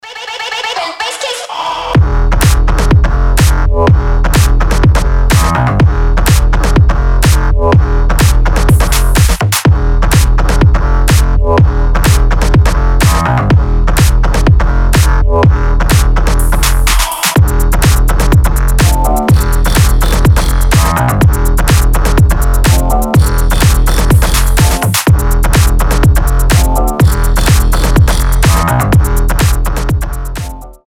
• Качество: 320, Stereo
ритмичные
жесткие
мощные
Electronic
EDM
Bass House
Мощный bass house